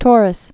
(tôrĭs)